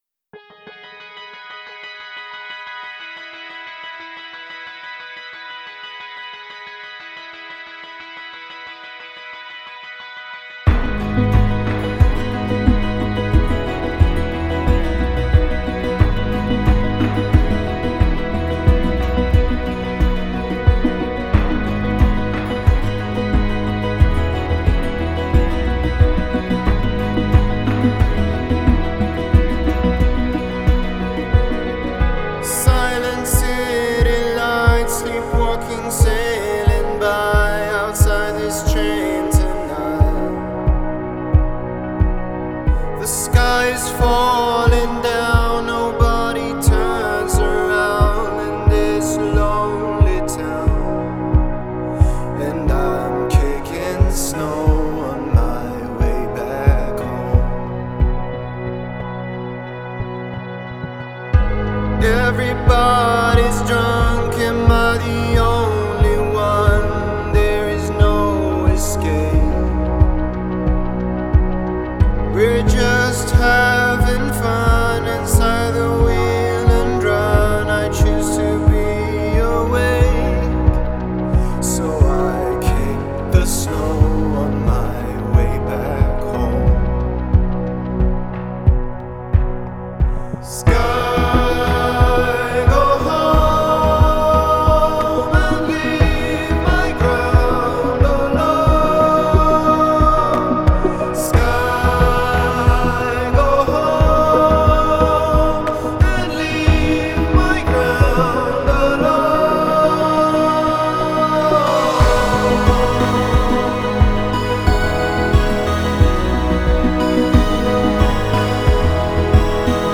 Vibrant, imposant, magique !